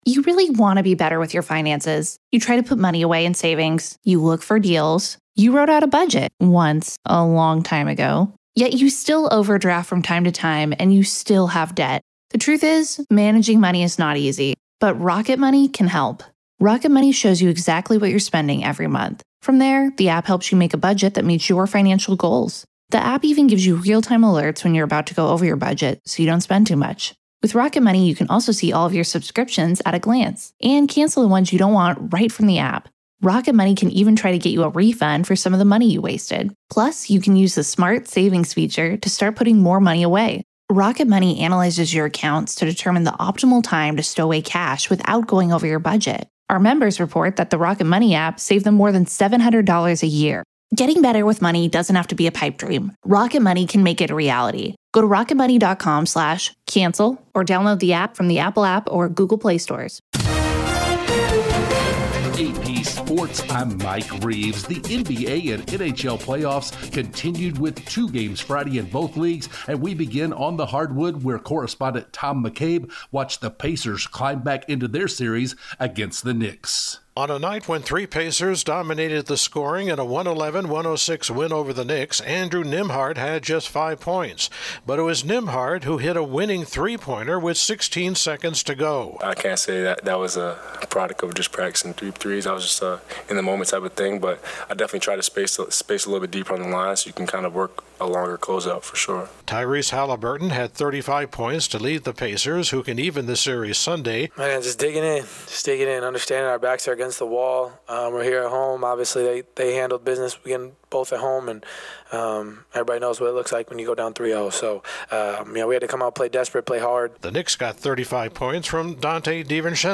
Facebook Twitter Headliner Embed Embed Code See more options The NBA and NHL playoffs continue, the Suns have their next head coach, Major League Baseball has all thirty teams in action, a standout basketball player at the University of Illinois is facing a court trial, Nelly Korda continues her quest for history and the PGA's Wells Fargo Championship continues. Correspondent